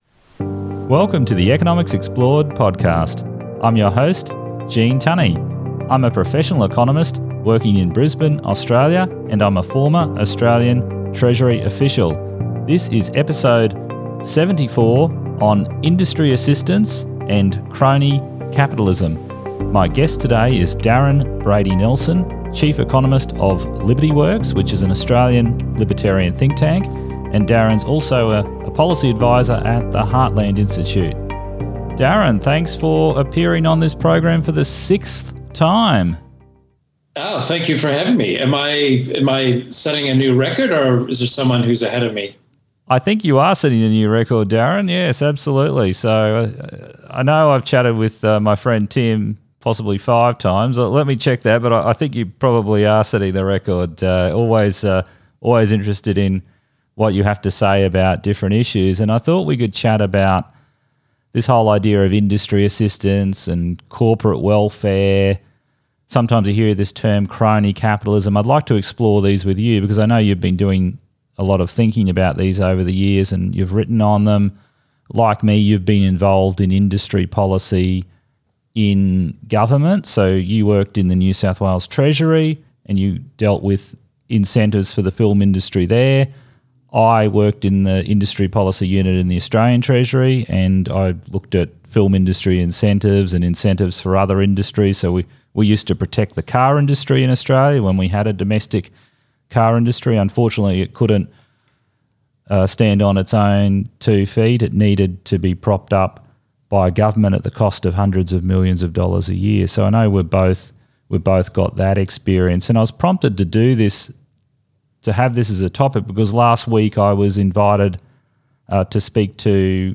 Consumers have noticed bags of chips, chocolates, and many other products have shrunk, but prices have not come down. This episode of Economics Explored features a detailed discussion on shrinkflation